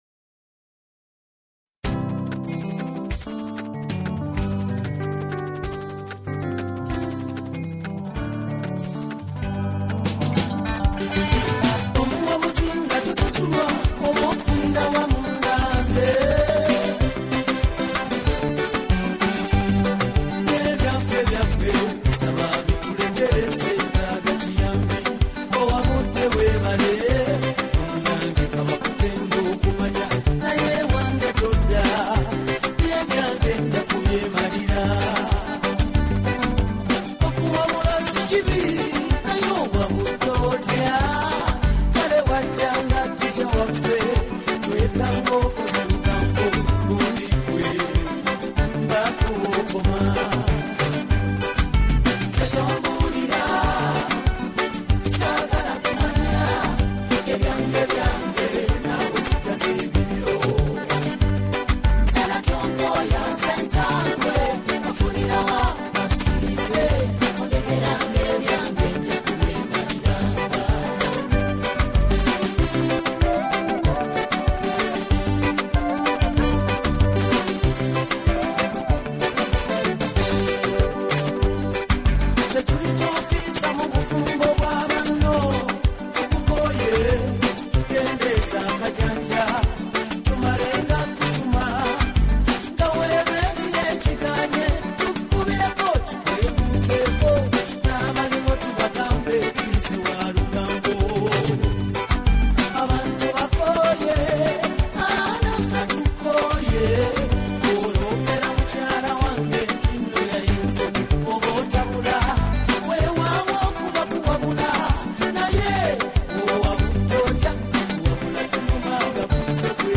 a powerful Ugandan song
In this emotionally driven track